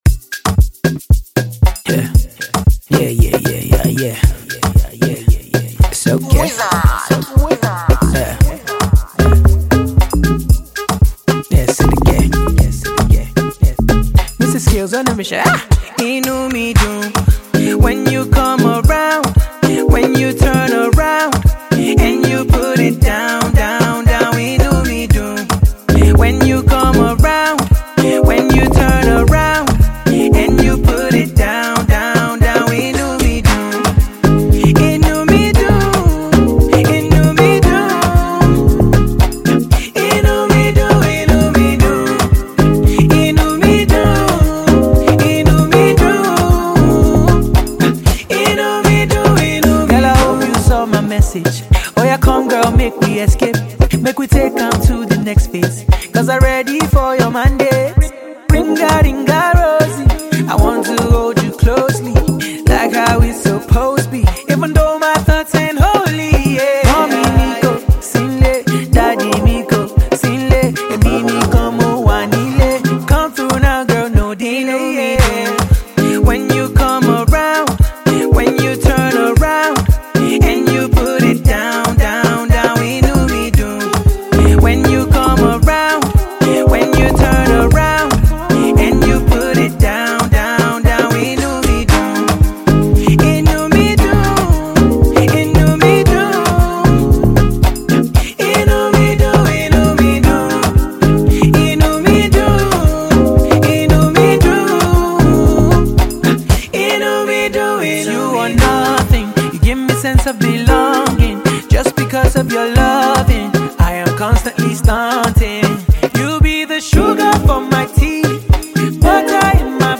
amapaino song